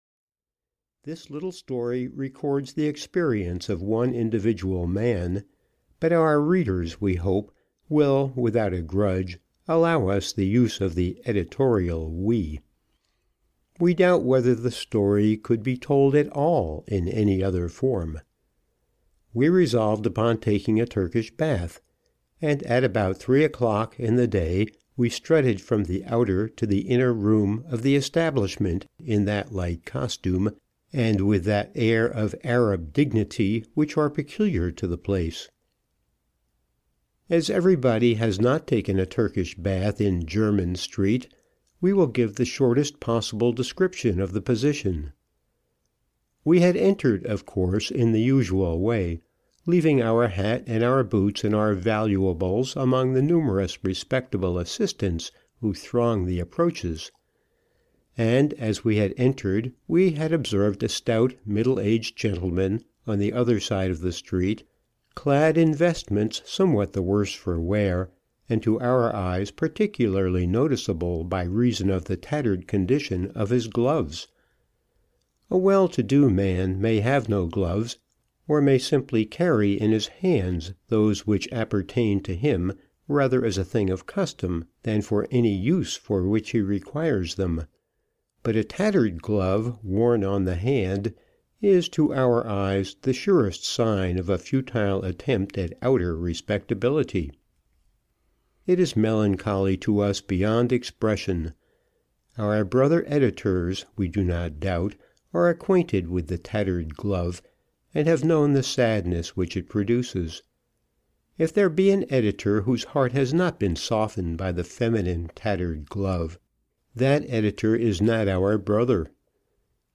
An Editor's Tales (EN) audiokniha
Ukázka z knihy